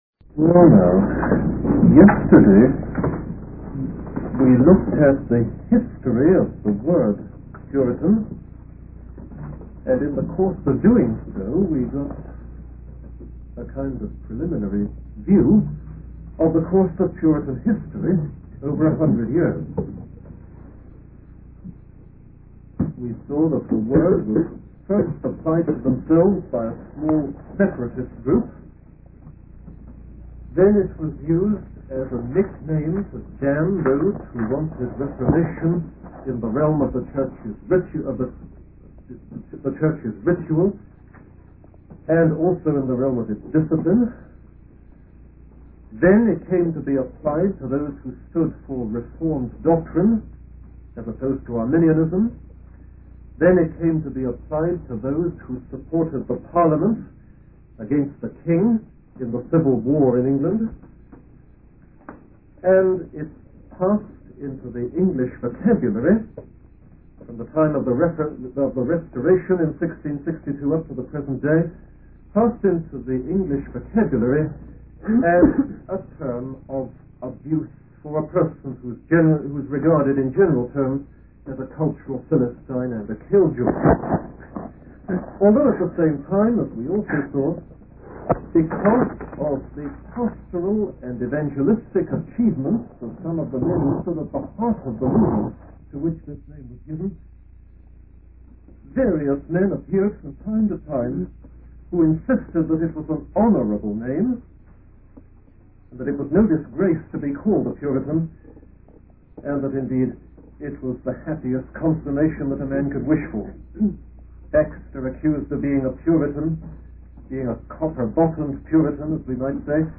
In this video, the speaker begins by encouraging the audience to ask questions and seek clarification during the lecture.